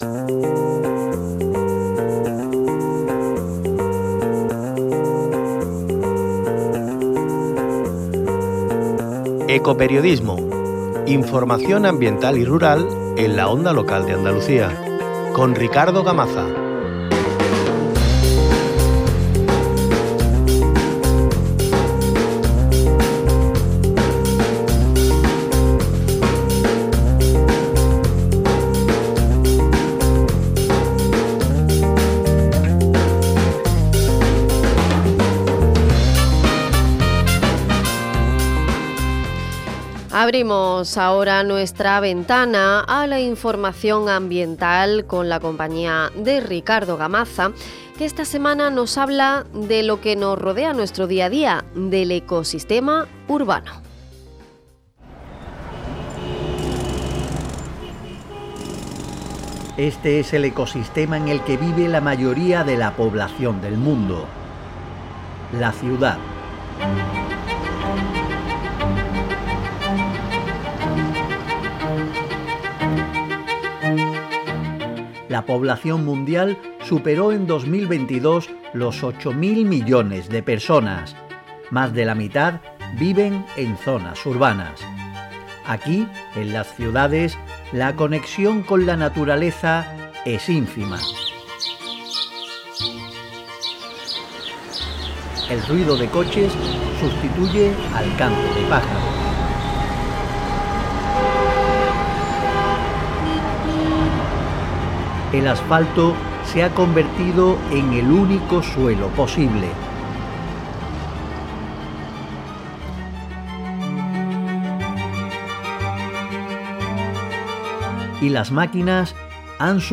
A través de las voces de quienes están detrás de proyectos como Huerto del Rey Moro, un espacio verde autogestionado por la asamblea de vecinas y vecinos La Noria y que fue recuperado del abandono administrativo en 2004, descubrimos cómo lo verde se abre paso entre el hormigón. Además, en este reportaje se plantea la siguiente pregunta: ¿Cómo afecta al ser humano que habita en las ciudades la progresiva desconexión con la naturaleza?